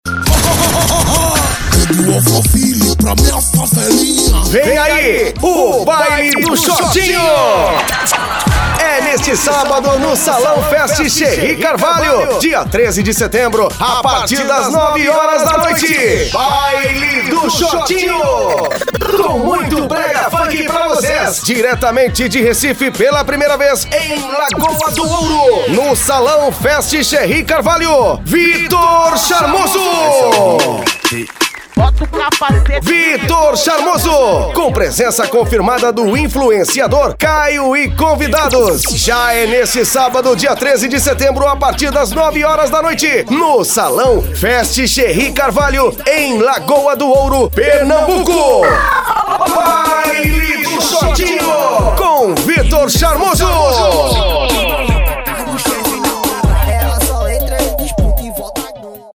VINHETA PARA FESTA ANIMADA: